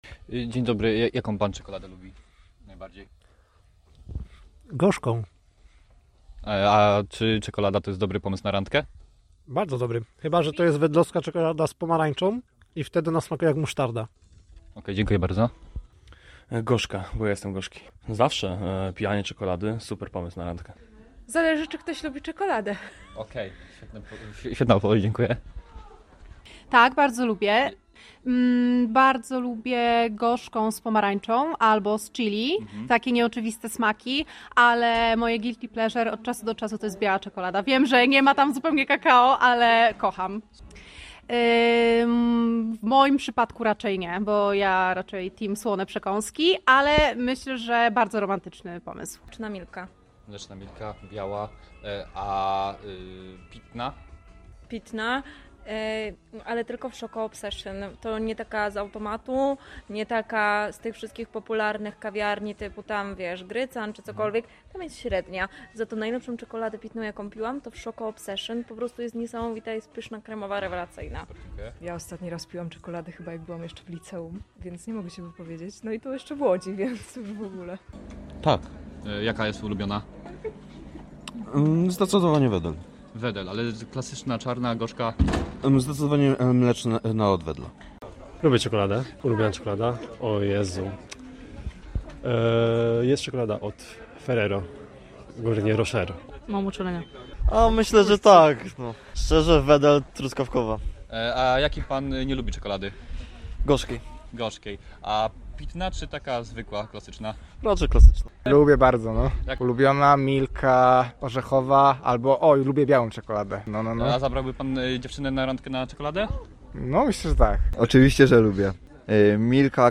Aby to uczcić redaktorzy Radia Fraszka, w swojej sondzie zapytali studentów UJK o to, jaka jest ich ulubiona czekolada oraz czy jest ona dobrym sposobem na randkę. Wśród respondentów królowała czekolada mleczna firmy Wedel, oraz zgoda, że wspólna czekolada z drugą połówką to niezły pomysł na udaną randkę.
Sonda-na-dzien-czekolady-7.mp3